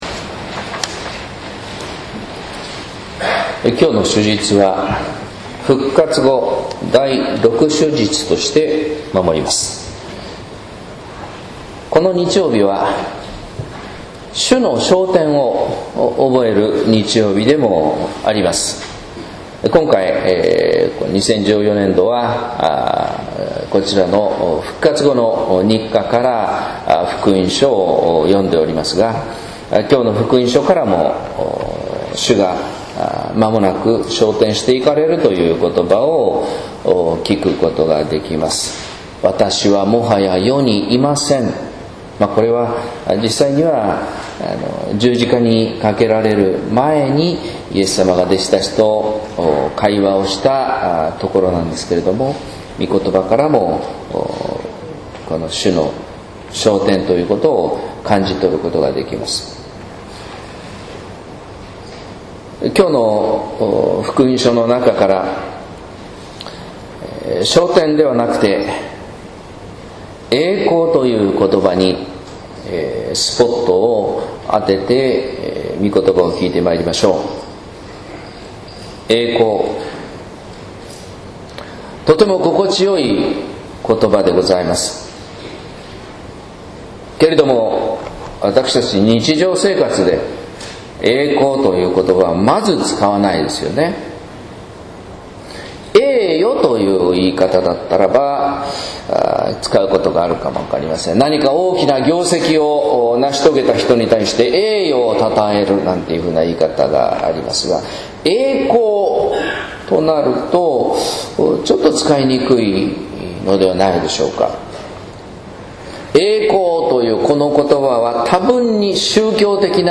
説教「初めの前の栄光」（音声版）